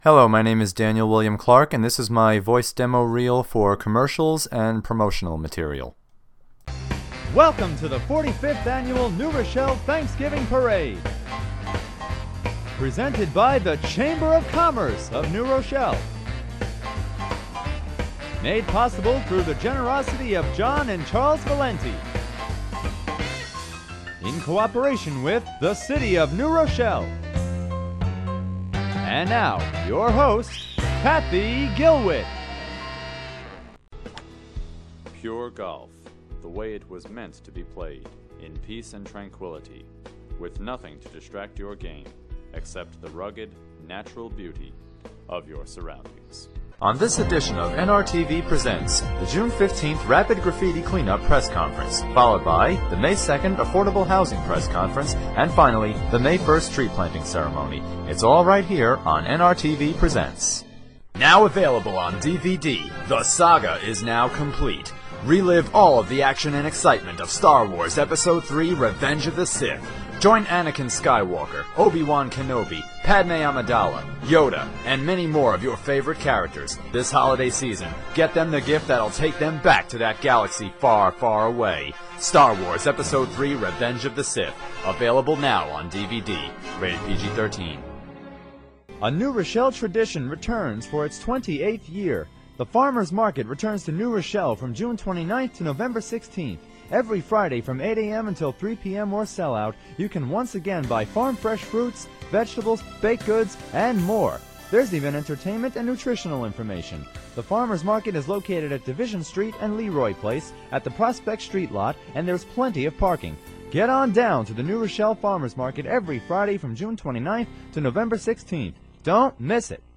voice-over artist for hire!
PSA, Commercial, and Announcement Reel